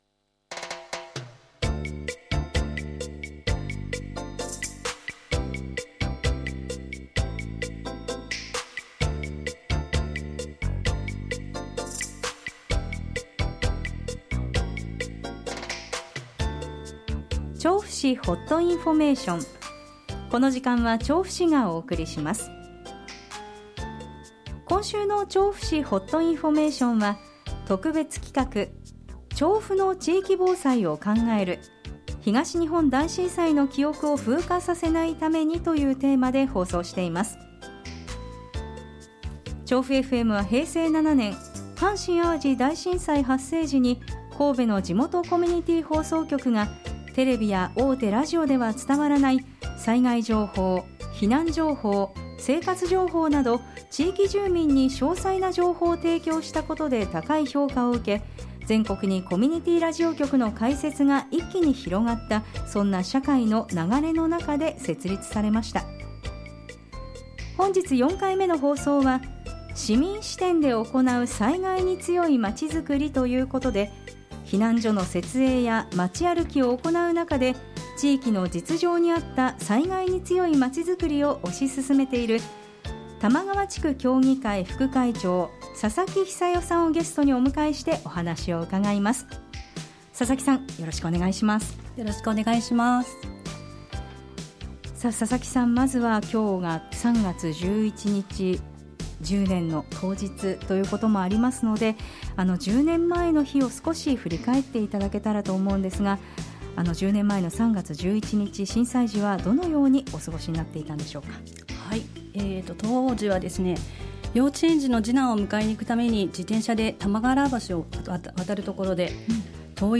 東日本大震災から１０年を迎えるにあたり，３月８日から１２日までの５日間，これまで東日本大震災をはじめ被災地とボランティア等で関わってきた方や，地域防災の担い手として活躍いただいている方などをゲストに迎えて，大災害の記憶を風化させないよう，当時の経験とあわせて災害への備えのヒントをお伺いしました。